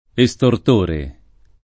vai all'elenco alfabetico delle voci ingrandisci il carattere 100% rimpicciolisci il carattere stampa invia tramite posta elettronica codividi su Facebook estortore [ e S tort 1 re ] (meglio che estorsore [ e S tor S1 re ]) s. m.